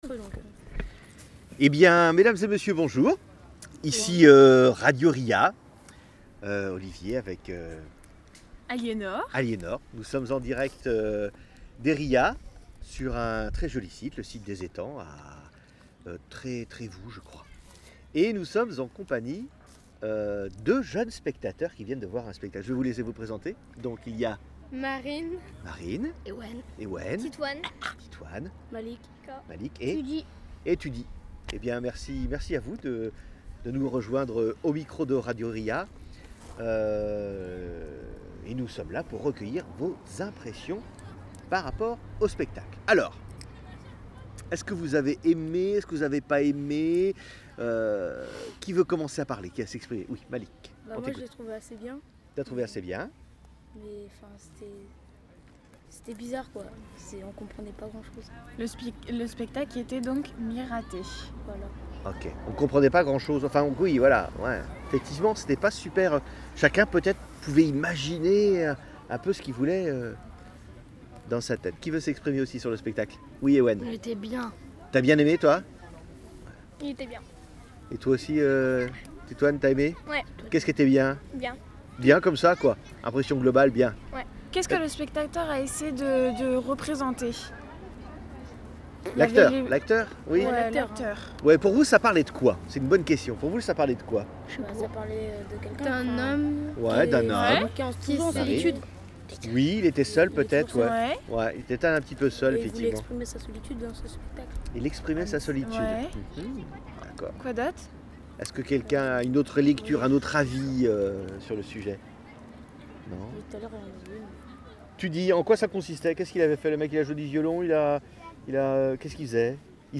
mercredi 29 août 2018 au Trévoux, au Pouldu et à Riec
Avant de prendre le repas, nous nous retrouvons à 12h12 pour assister au spectacle "Mira T" du Circ Panic.
Le spectacle est en apesanteur, aérien.